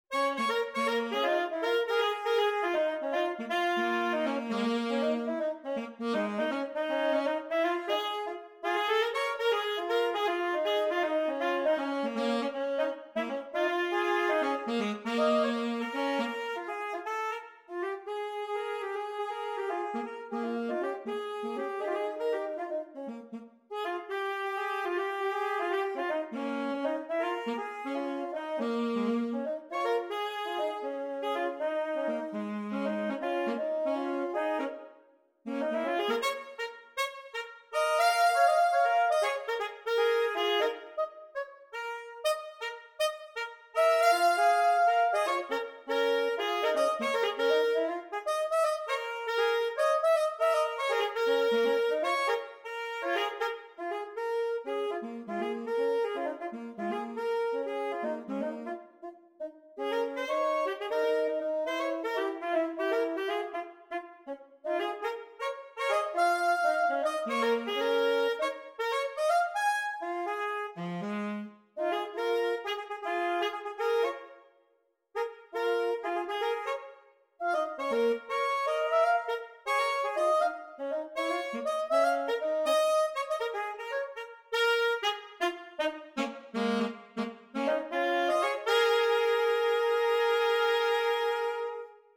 Gattung: Für 2 Altsaxophone